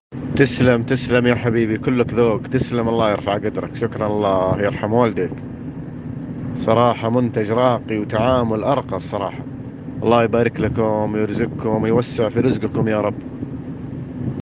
التوصيات الصوتية من عملائنا
توصية صوتية 5